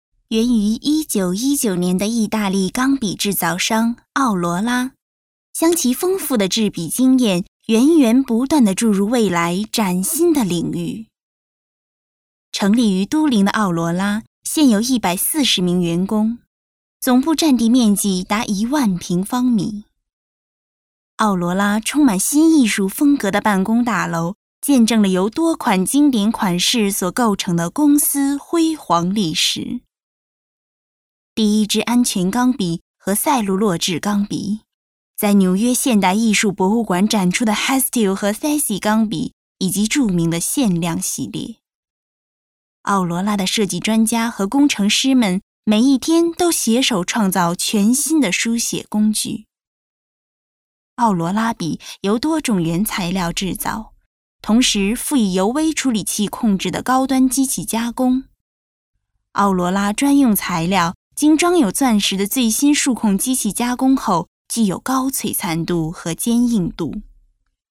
Professionelle chinesische Sprecherin für Werbung, TV, Radio, Industriefilme und Podcasts
Sprechprobe: Werbung (Muttersprache):
Professional female voice over artist from China.